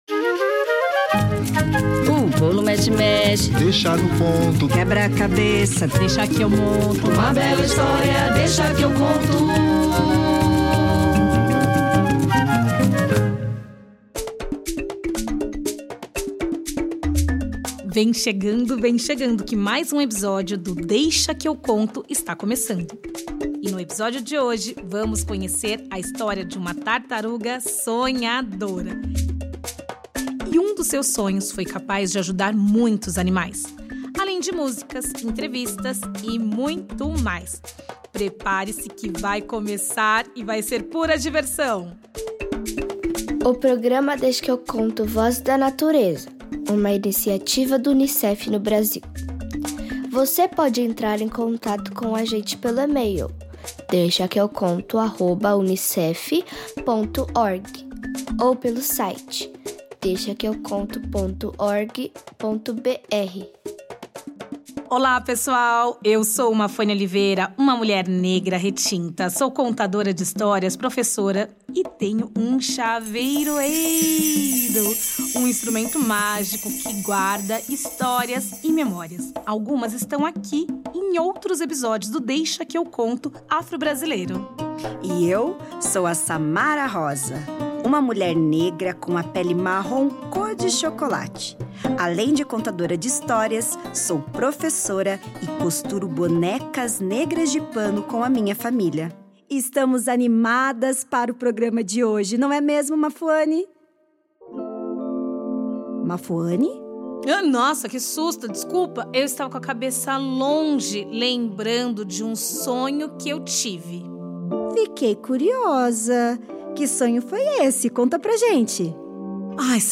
Além de músicas, entrevista com a equipe do Prato Verde Sustentável e muito mais!